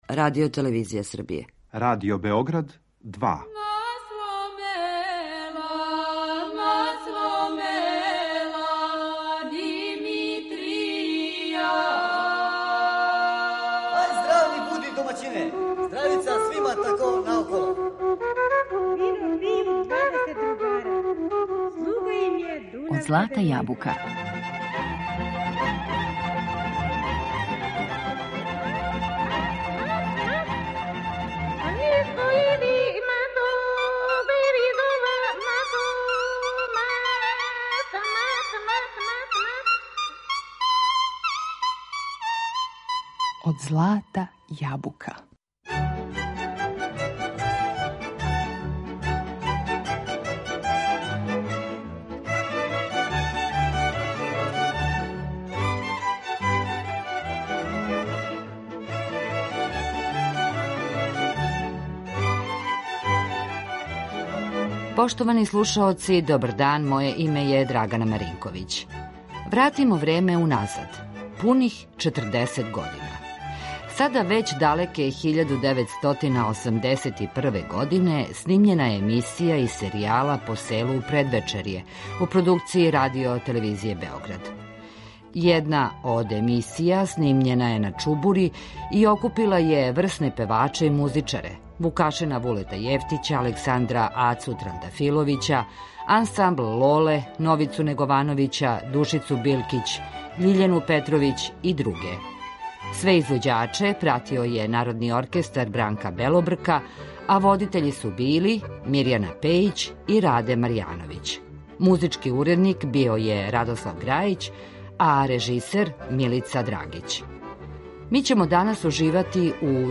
Пре пуних четрдесет година, сада већ далеке 1981, на Чубури је снимљена емисија из серијала „Посело у предвечерје”, у продукцији Радио-телевизије Београд.
Окупила је врсне певаче и музичаре
У данашњој емисији Од злата јабука уживаћемо у вредном документарном звучном материјалу из емисије „Посело на Чубури", у коме су сачувани песме и говор легендарних уметника ‒ солиста Радио Београда.